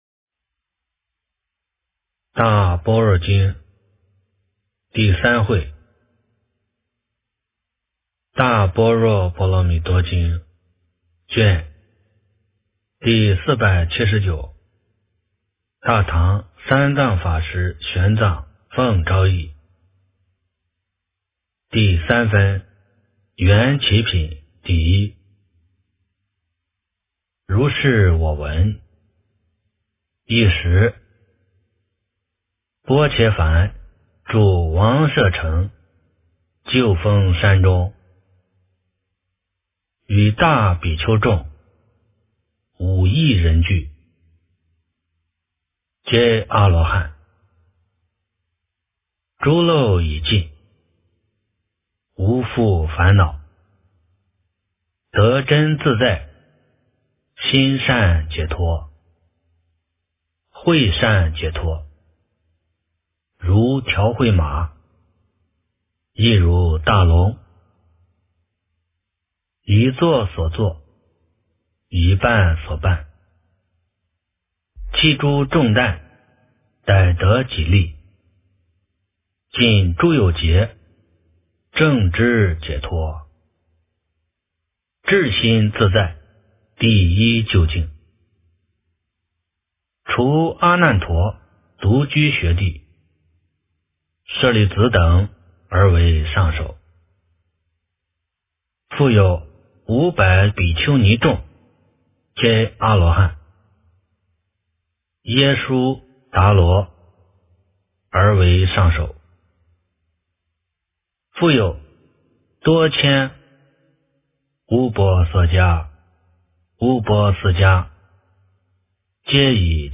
大般若波罗蜜多经第479卷 - 诵经 - 云佛论坛